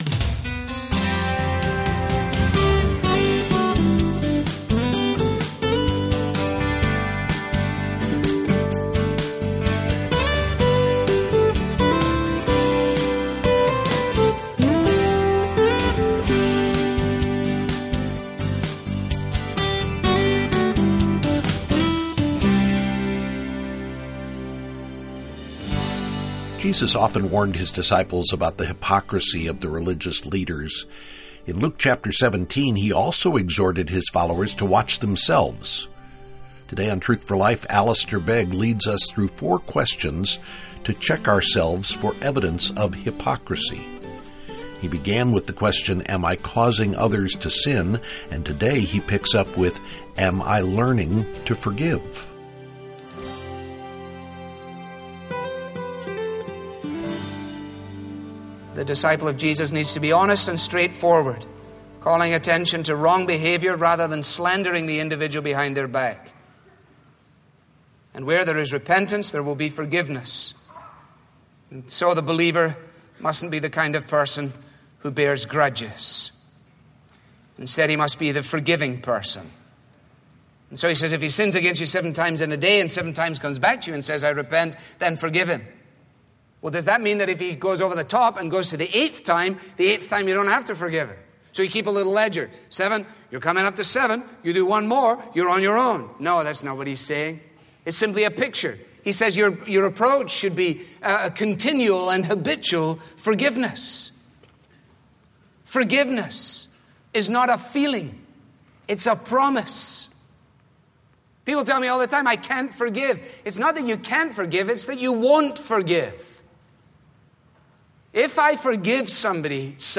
This listener-funded program features the clear, relevant Bible teaching